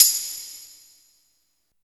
27 V.TAMB -L.wav